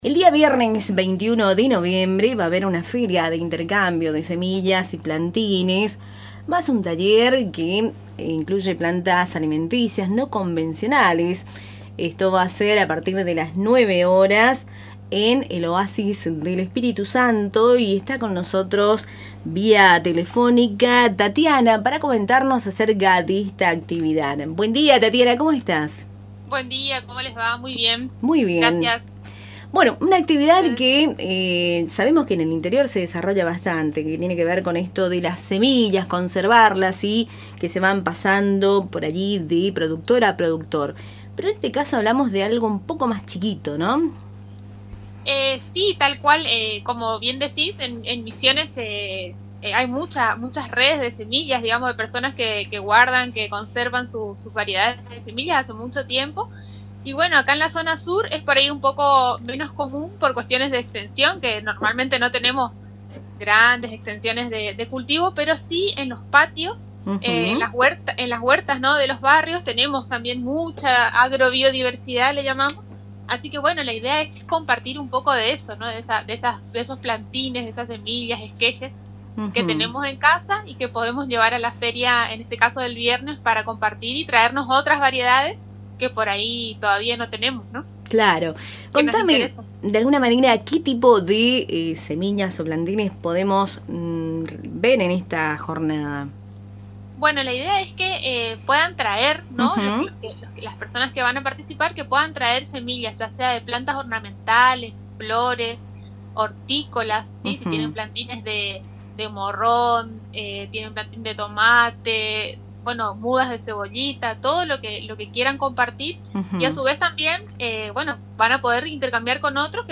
Escuchá la entrevista completa, realizada desde Tupambaé: